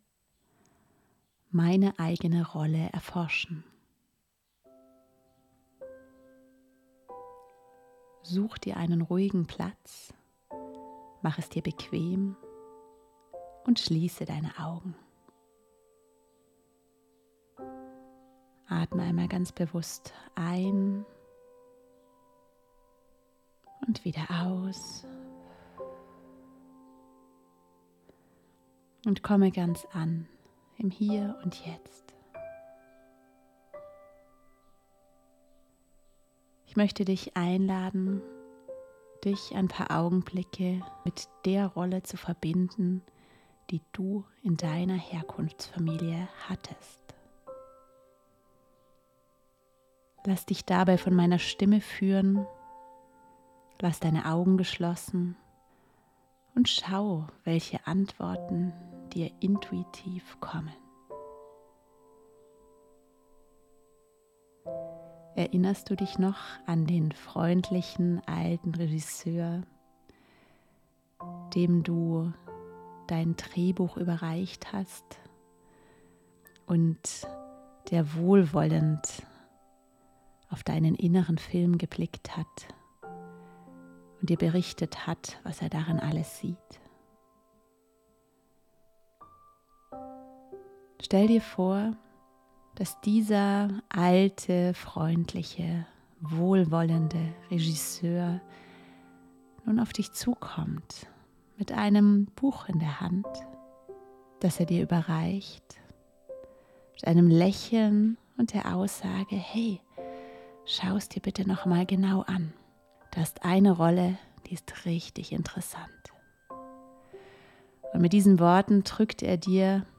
Gedankenreise: Eigene ROlle